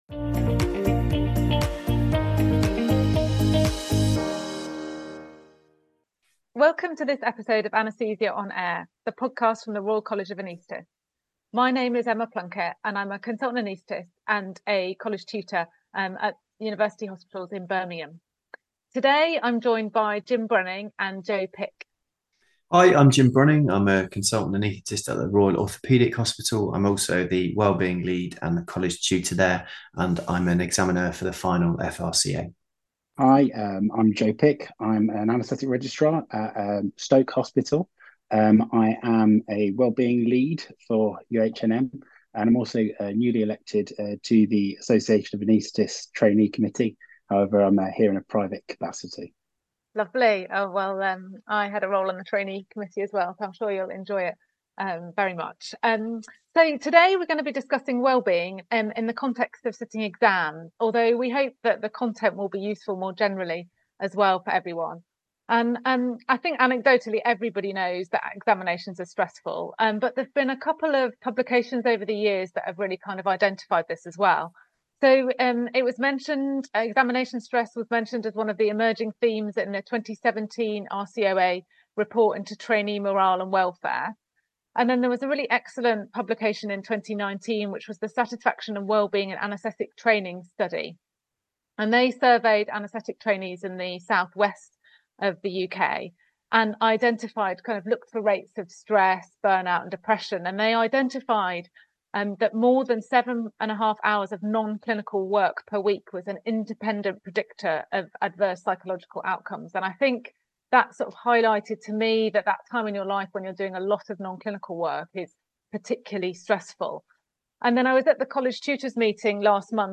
This discussion is accompanied by a guided relaxation audio resource, also available in your Anaesthesia on Air podcast feed, or to download at the link below.